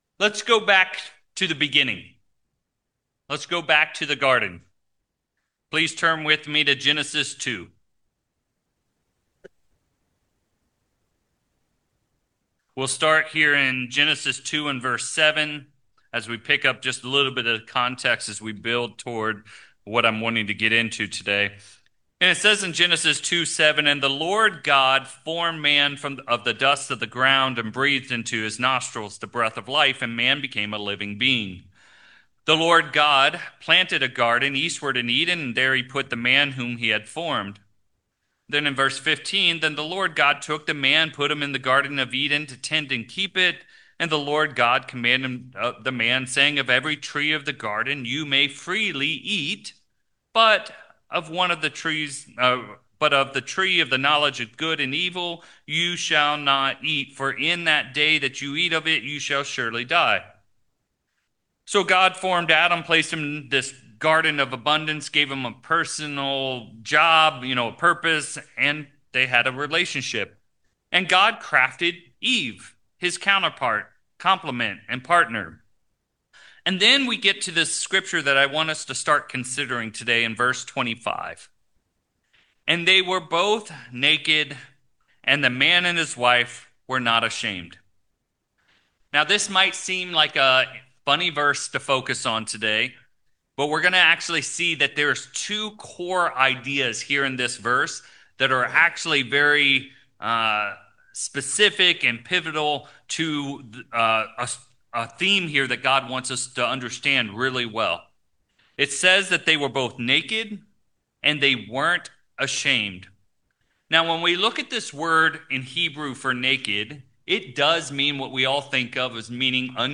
Given in San Francisco Bay Area, CA Petaluma, CA